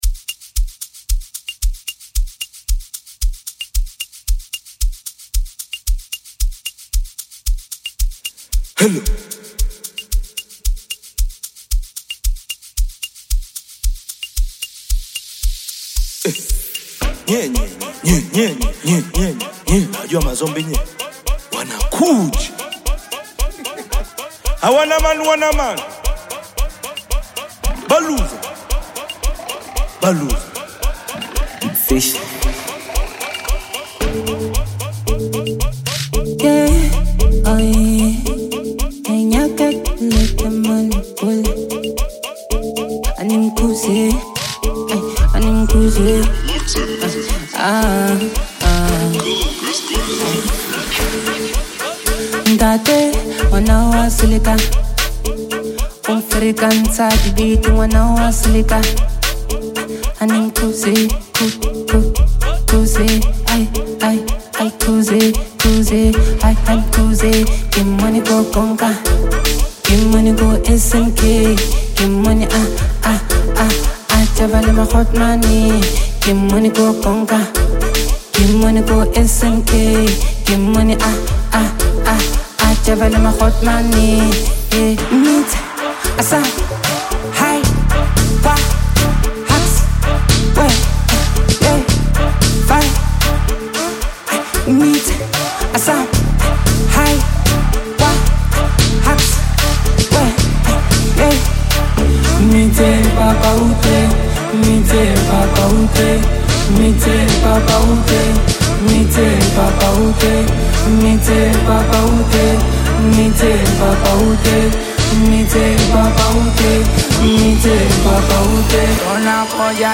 high-energy Amapiano/Bongo Flava anthem
Genre: Amapiano